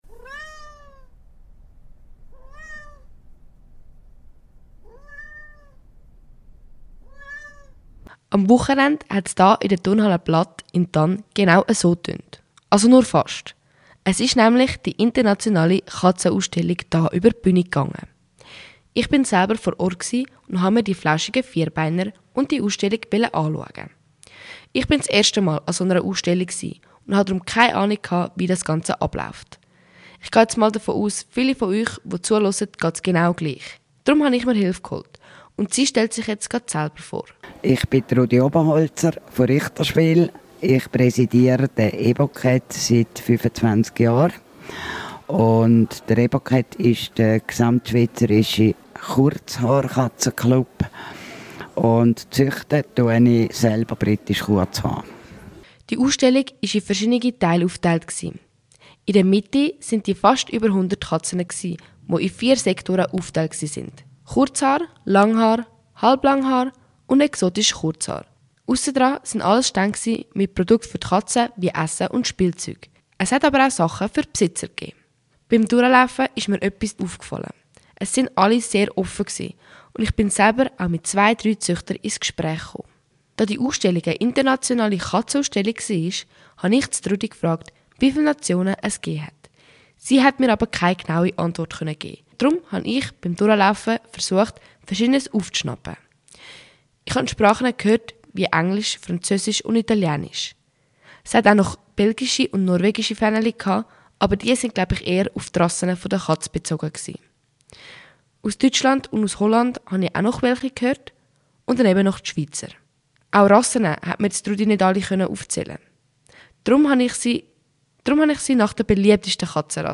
In diesem Radiobeitrag berichte ich von meinem Besuch an einer Internationalen Katzenausstellung – in der Turnhalle Blatt in Tann.